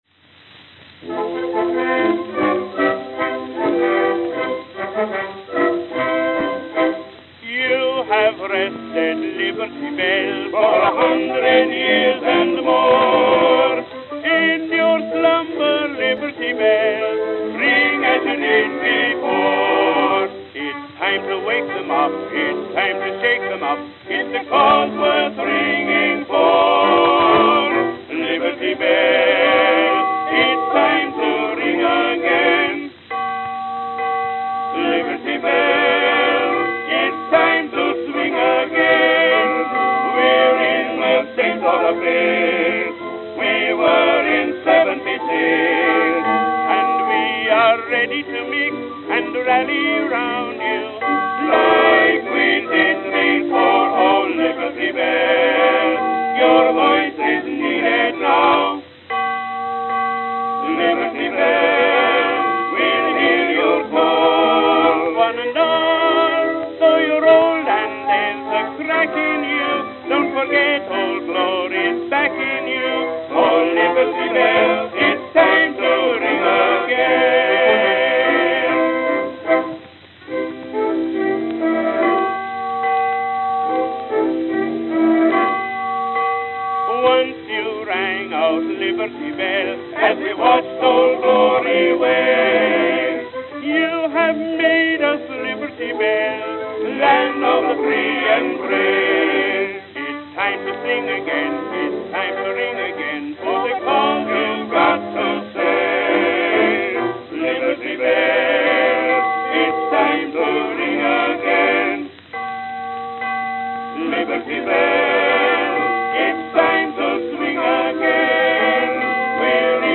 Victor 10-Inch Double-Sided Acoustical Records